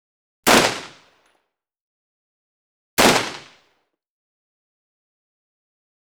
314                                                    <!-- Rifle Sound -->
RifleFire.wav